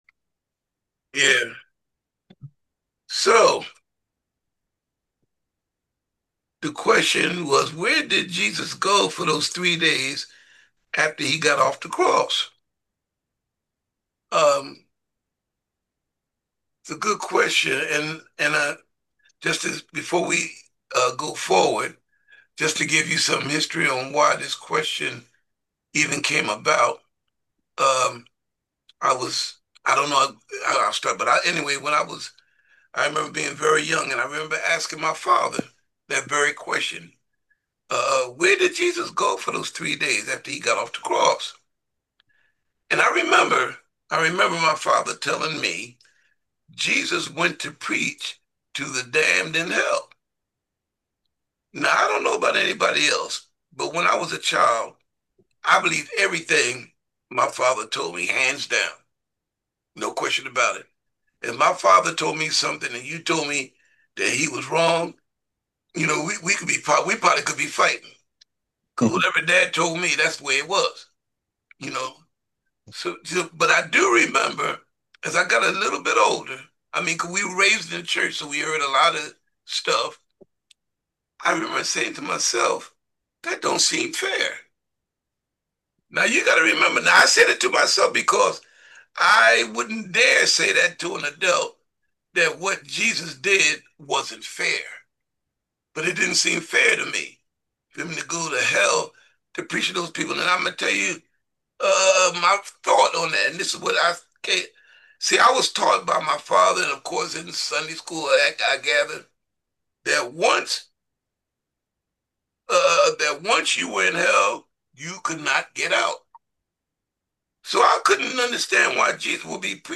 WEDNESDAY NIGHT BIBLE STUDY: Where Did Jesus Go for those Three Days He was Off the Cross?